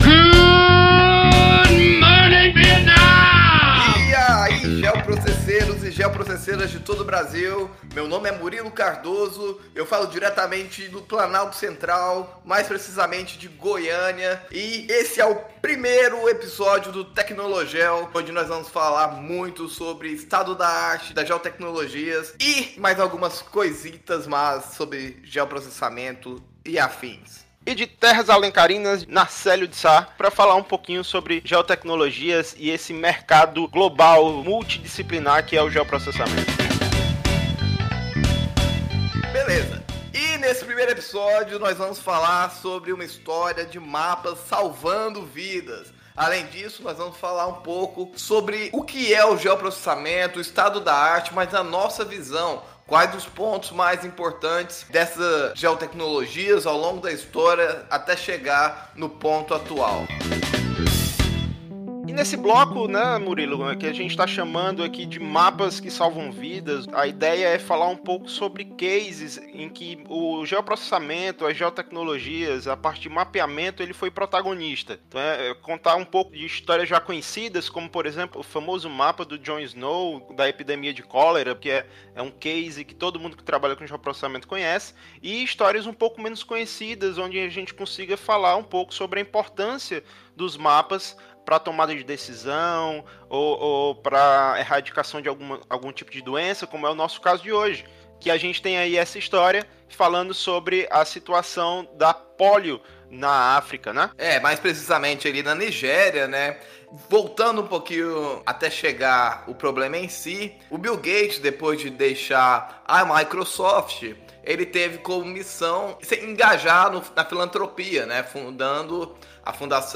Neste podcast: O TecnoloGEO 01 traz até você um bate-papo descontraído sobre geoprocessamento, geotecnologias, formação acadêmica e mercado de trabalho.